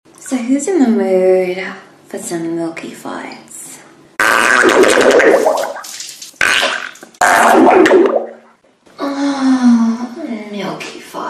Play, download and share Milky Farts original sound button!!!!
milky-farts.mp3